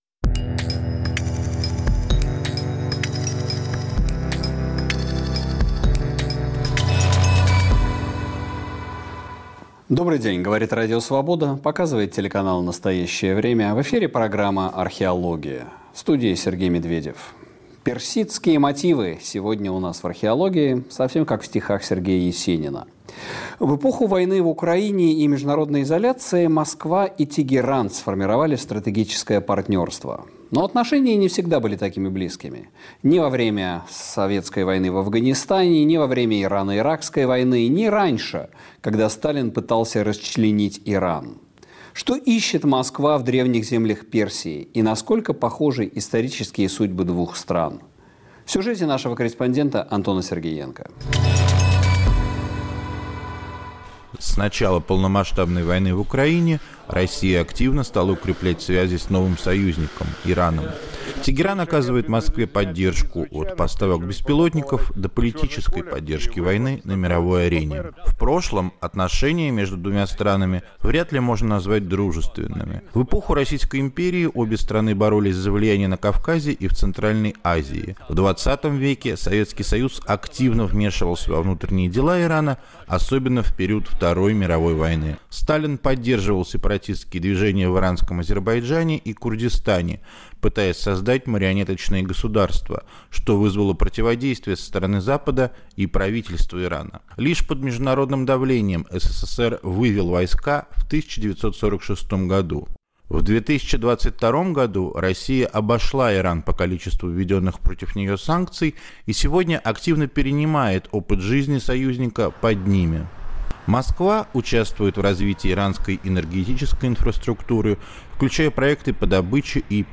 политолог
журналист и востоковед